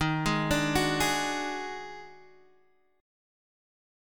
D# Minor Major 7th Double Flat 5th